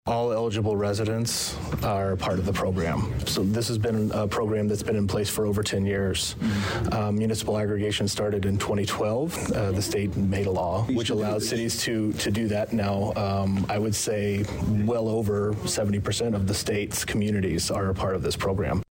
speaks to Danville City Council during Tuesday, March 21st meeting.